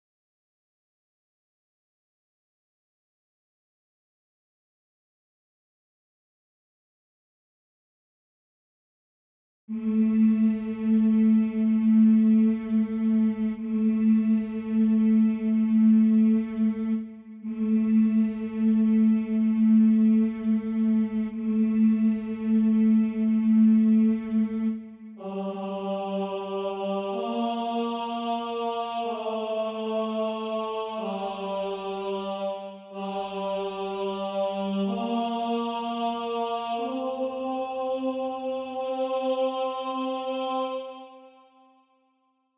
Musikerboard-Stimmen für einen vielstimmigen Chor.
Wie gesagt, es geht nur um "mmmm" und "aaaaaa" your_browser_is_not_able_to_play_this_audio Noten, Midis und ein Playback mit passener Stimme im Hintergrund zur Orientierung kann ich liefern!